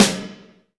PLATE SNAR06.wav